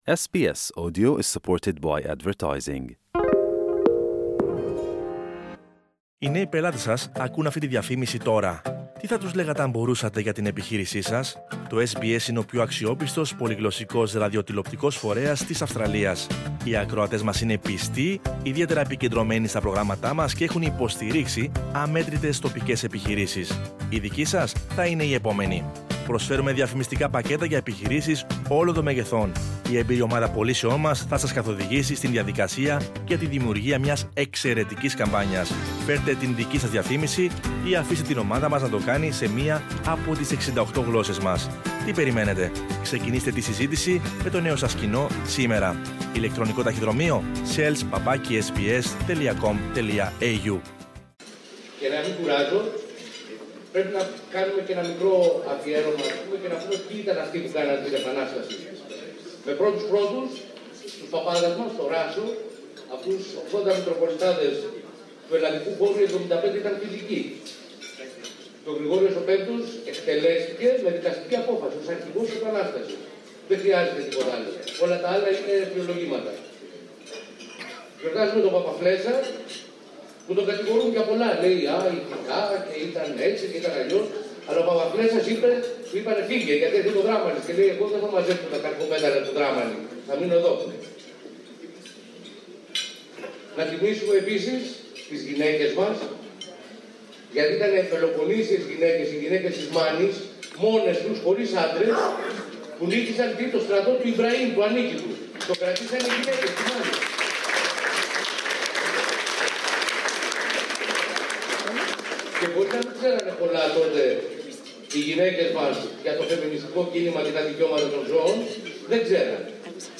The Consul General’s speech on the Papaflessas’ dinner dance created strong reactions among women participants at the event by the Pammessinian Brotherhood of Melbourne on 25th of March.